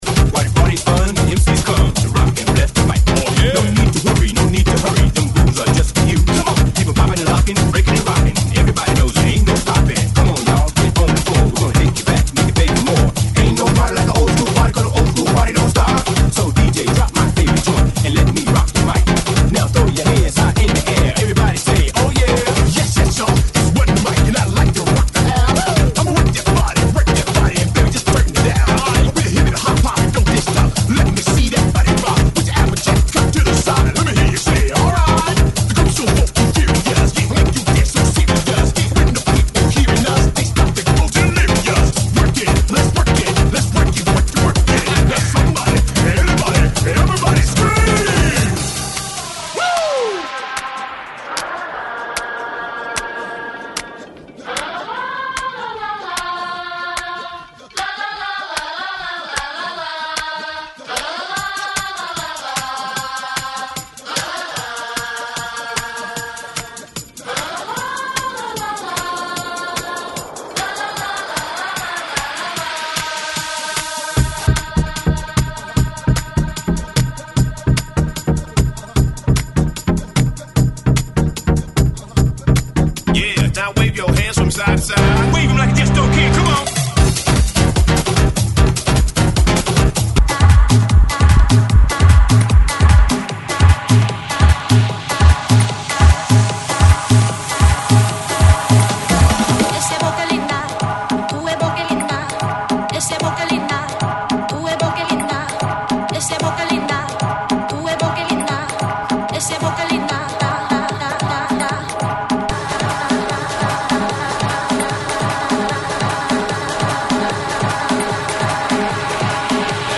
GENERO: REMIX